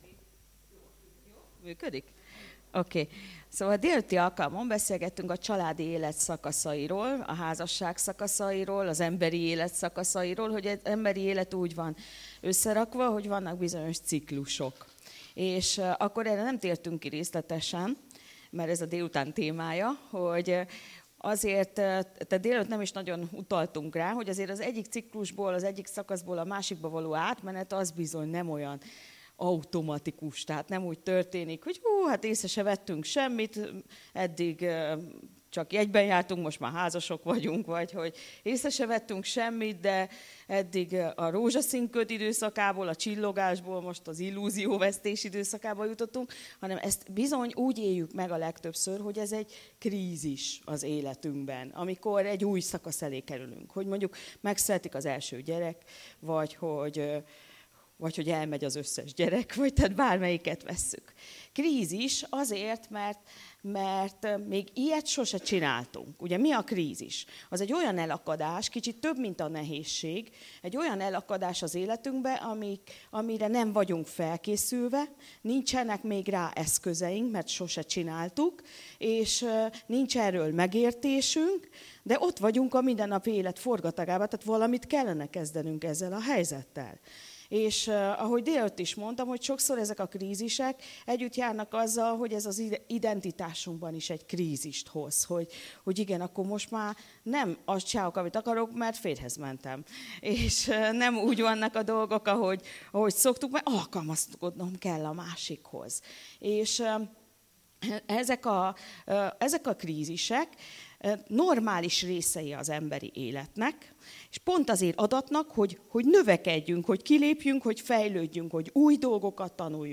Tanítások